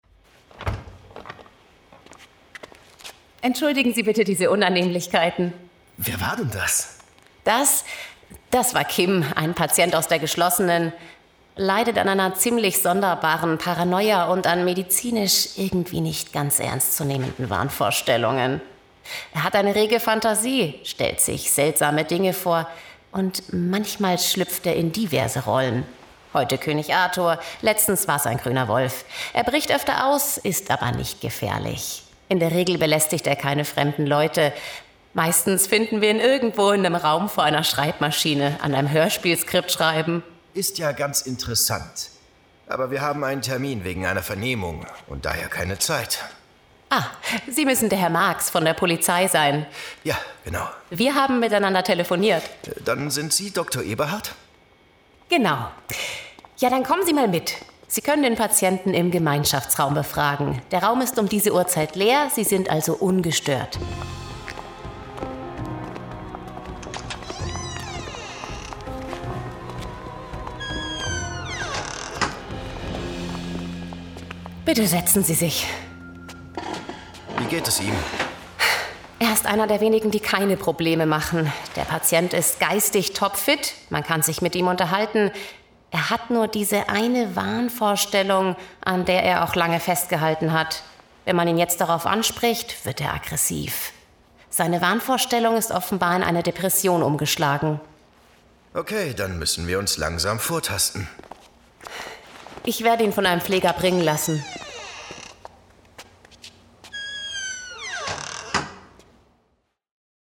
dunkel, sonor, souverän, hell, fein, zart, markant, plakativ, sehr variabel
Mittel minus (25-45)
Bayrisch
Hörbuch Monsterparty
Audiobook (Hörbuch)